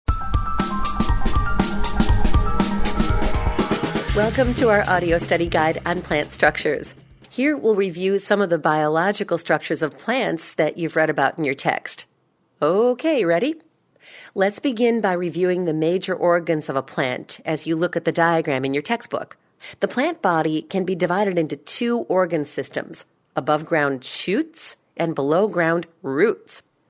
Warm, melodic, rich, and resonant - from sparking to sexy.
englisch (us)
Sprechprobe: eLearning (Muttersprache):